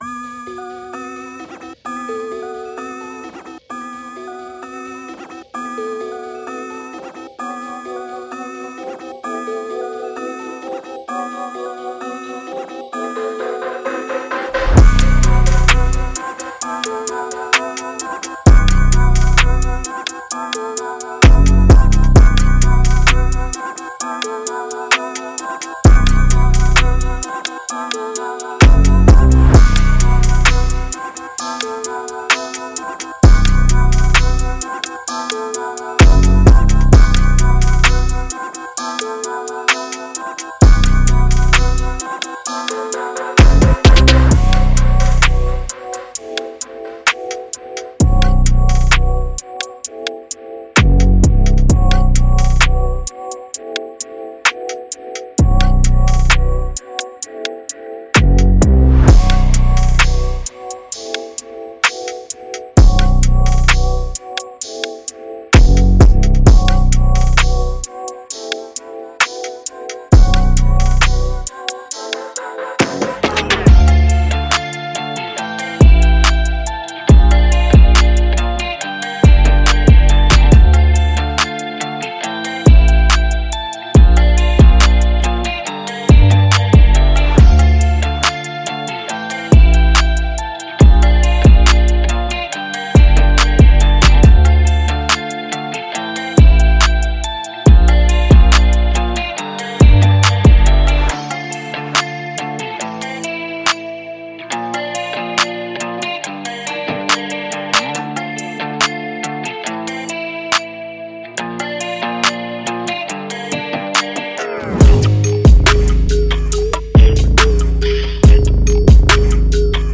Hip HopTrap
• 17 x Bass Guitars
• 11 x Reese Basses
• 15 x Bass Plucks
• 42 x Clean 808s
• 55 x Distorted 808s
• 10 x Misc Basses